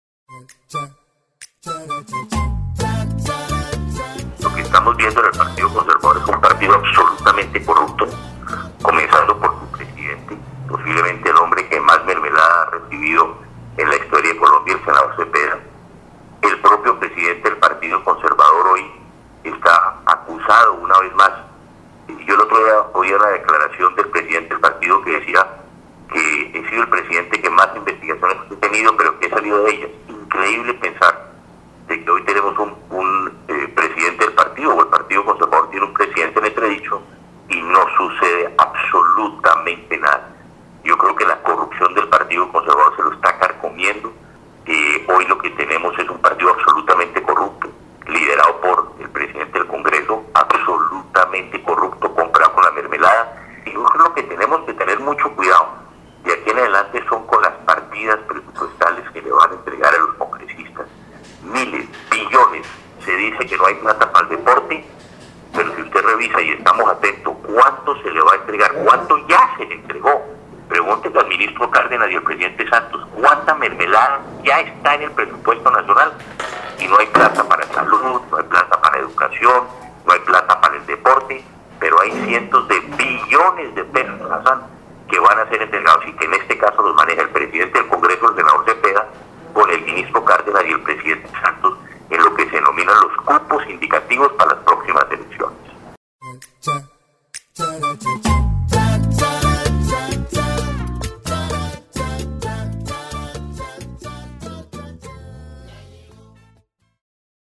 En diálogo con LA FM, Pastrana dijo que la corrupción está carcomiendo el Partido Conservador.